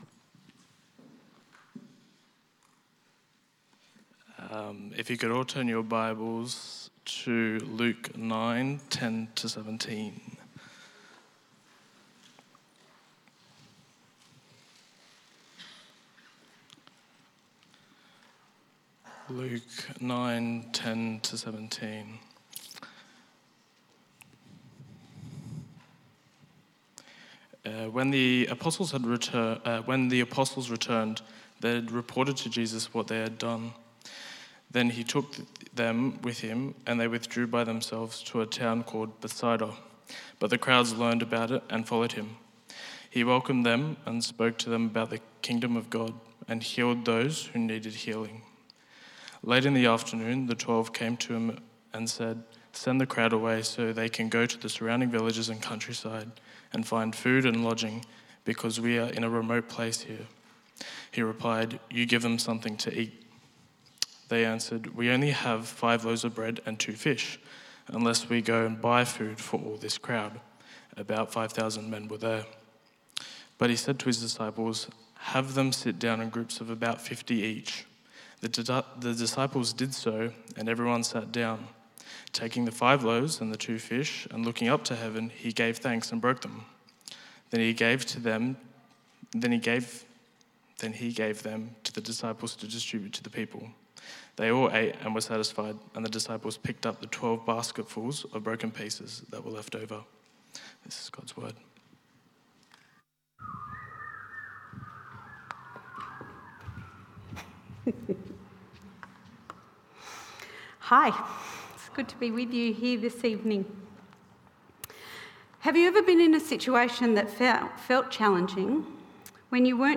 Luke 9:10-17 Service Type: 6PM  This Sunday